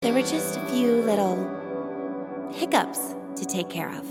Play, download and share HICCUPS original sound button!!!!
hiccups.mp3